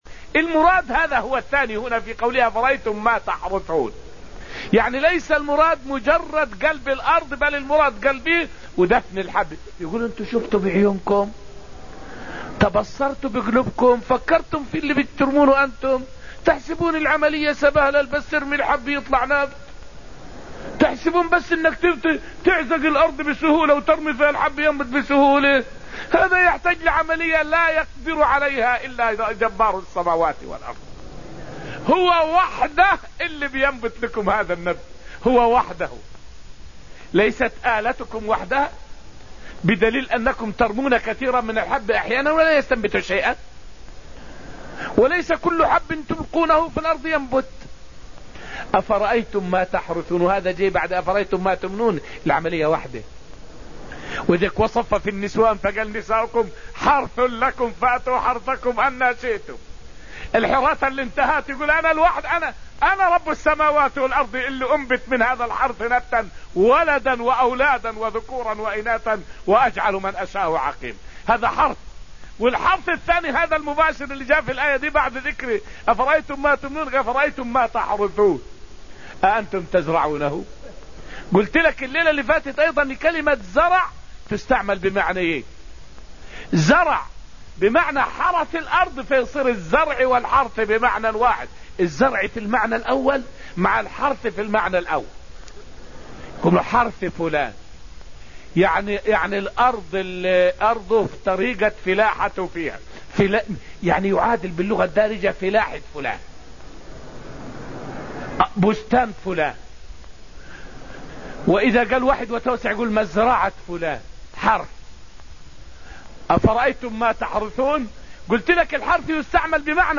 فائدة من الدرس السابع من دروس تفسير سورة الواقعة والتي ألقيت في المسجد النبوي الشريف حول آيات الله في إنبات الثمار والزروع.